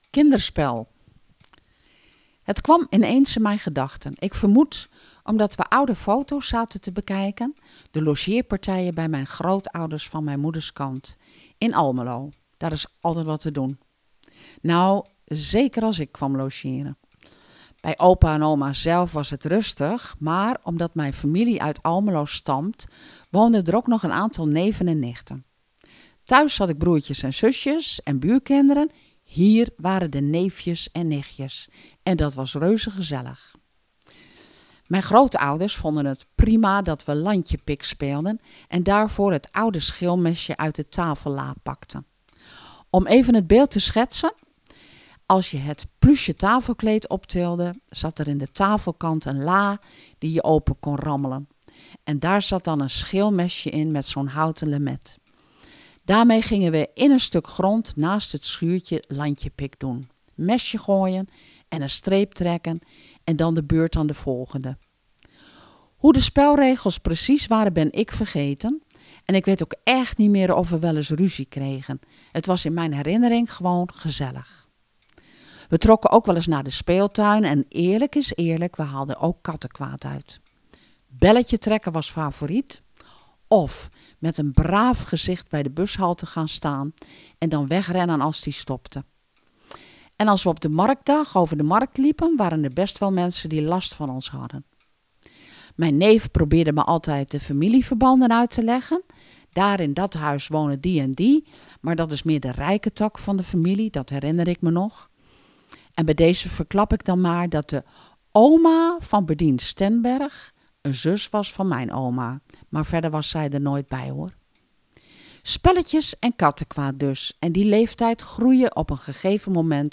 Kletskoek wordt iedere vrijdagochtend vanaf 10 uur tot 13 uur "live" vanuitde studio van Radio Capelle wordt uitgezonden.